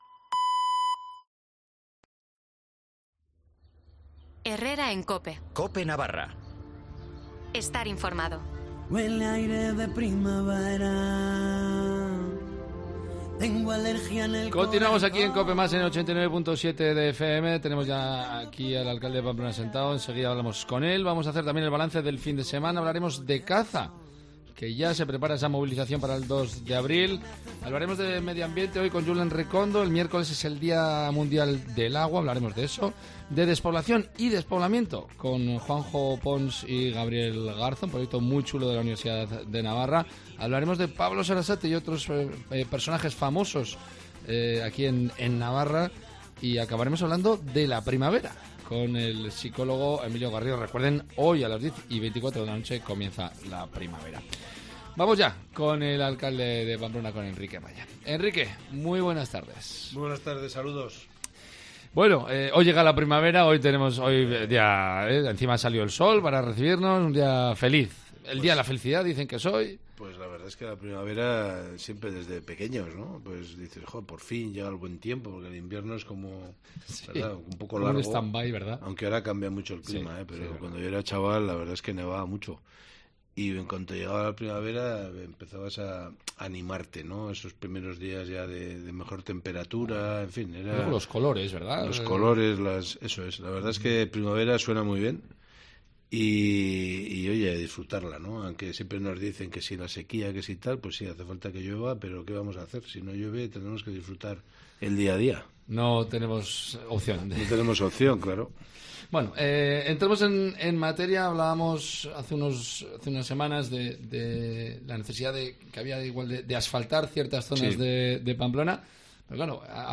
Enrique Maya, alcalde de Pamplona habla de la necesidad y de los problemas de asfaltar en Pamplona, de la llegada el 10 de septiembre de una etapa de la vuelta ciclista a España (dos días después de la celebración del Privilegio de la Unión) y de la problemática vecinal con la rotonda de San Jorge. Además, el alcalde habla de la campaña de ayuda a Apymas y campaña de desratización con la llegada de la primavera.